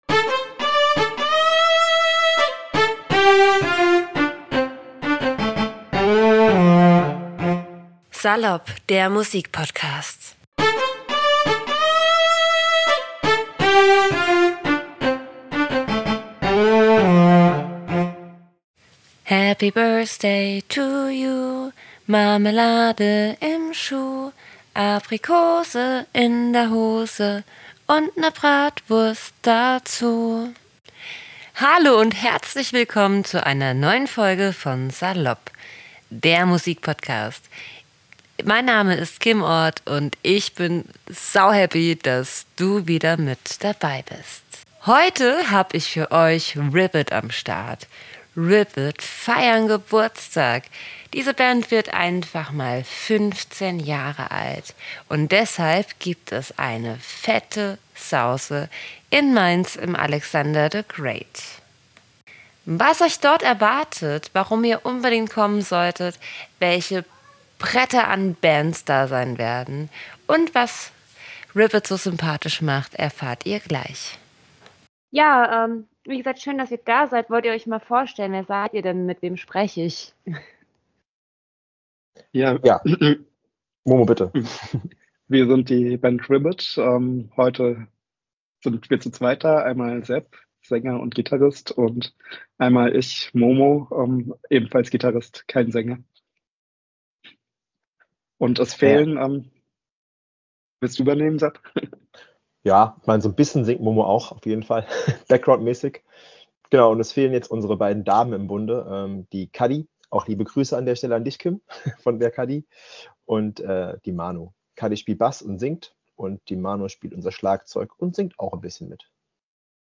In diesem Interview mit der Mainzer Band "Ribbit" erfahrt ihr, wie diese Band ihren Geburtstag feiert und wohin der Erlös geht.